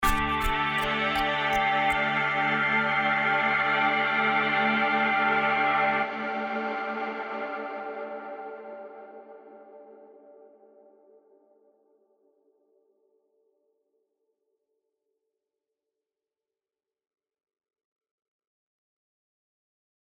パッチ・ミューテーションとは、選択しているプリセットをワンクリックで様々なサウンドに変化させる機能です。
実際に「Ambient Dreams」というディレクトリーにある「Pizzicato Grains」を使用してパッチ・ミューテーション機能で得られたサウンドバリエーションとの違いを聴き比べてみてください。
以下のデモ音源「original.mp3」が元のパッチのサウンド、「mutation.mp3」がパッチ・ミューテーション機能で得られたサウンドの中の1つです。